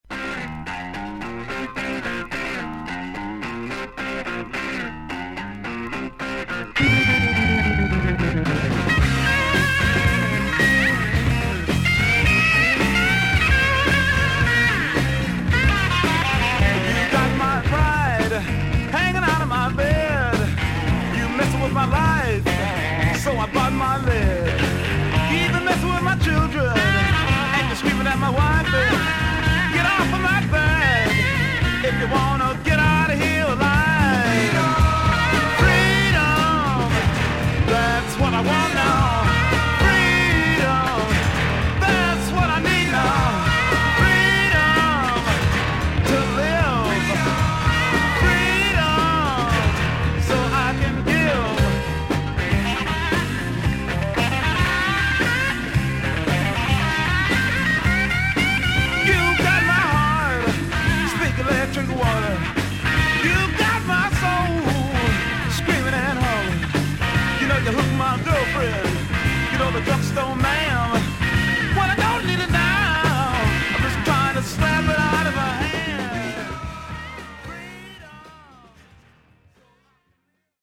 VG++〜VG+ 少々軽いパチノイズの箇所あり。クリアな音です。